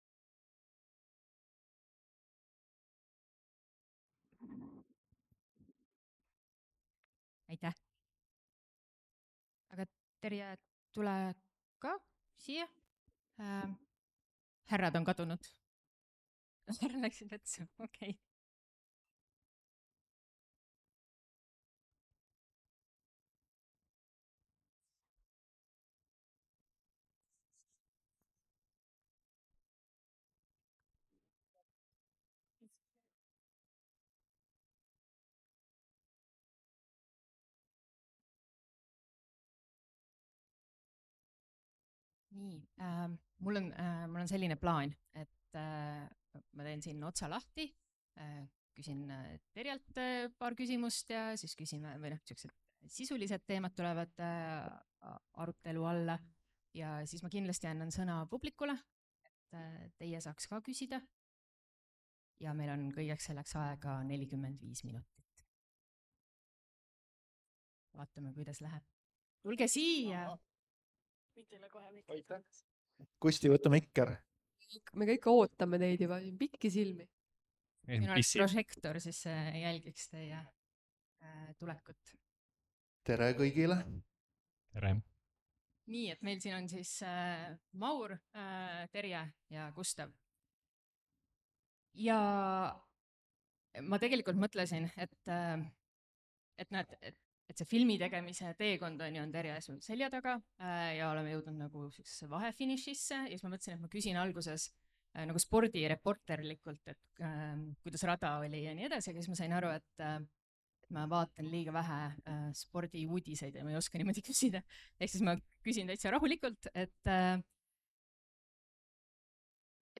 NB! Vestluse salvestus sisaldab sisurikkujaid.
piirideta_polvkond_vestlus.mp3